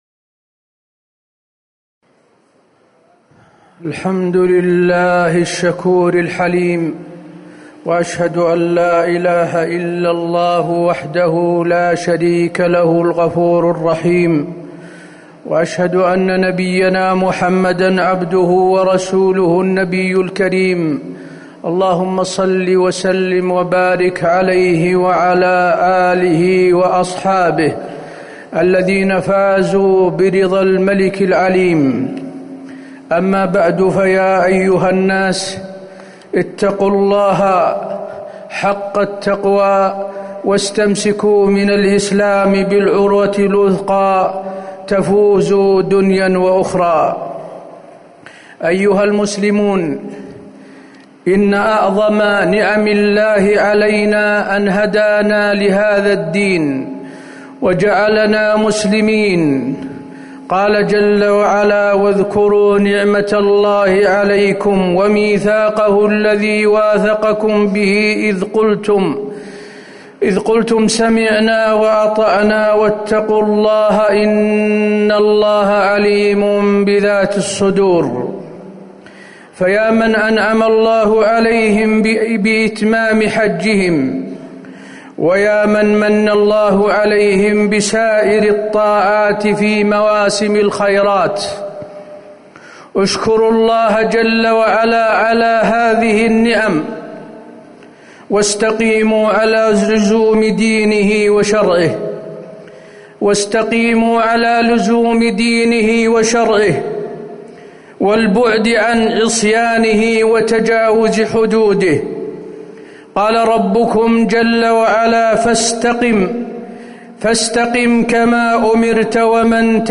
تاريخ النشر ١٥ ذو الحجة ١٤٤٠ هـ المكان: المسجد النبوي الشيخ: فضيلة الشيخ د. حسين بن عبدالعزيز آل الشيخ فضيلة الشيخ د. حسين بن عبدالعزيز آل الشيخ الاستقامة